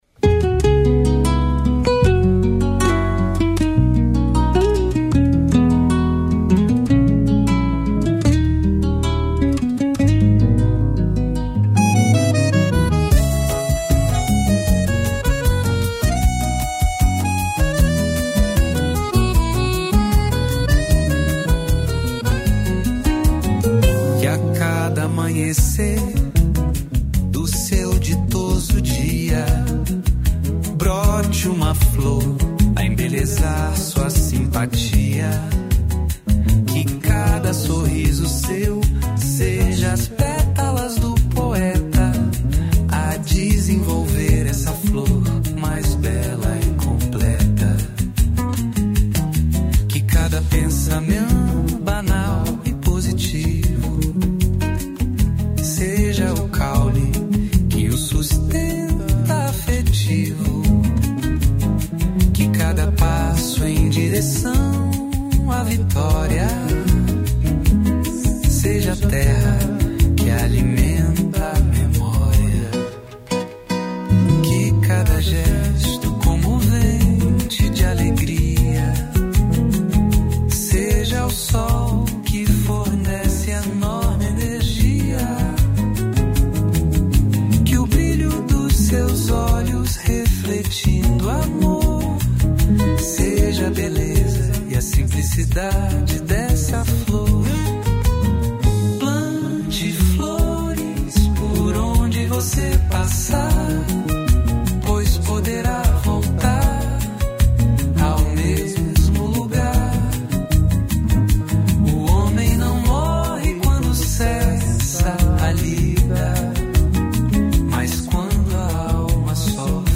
Violão